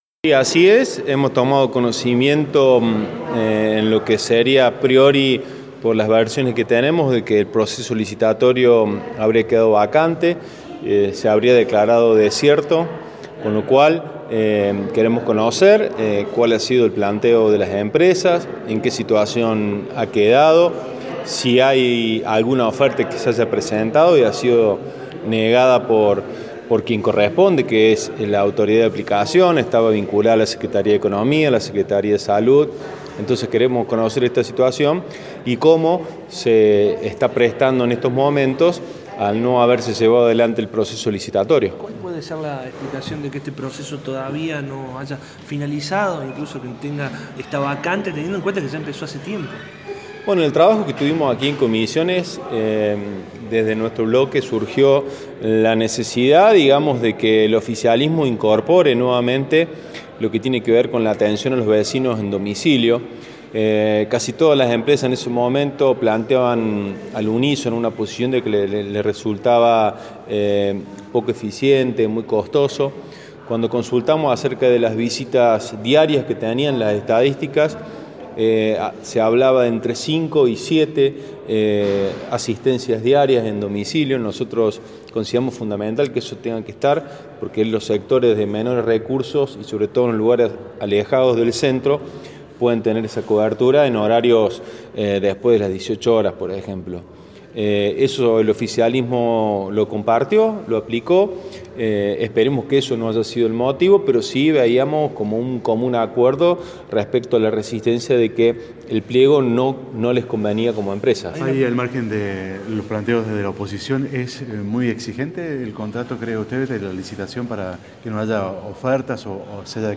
Martín Carranza, presidente del bloque de concejales de Cambiemos así explica su postura: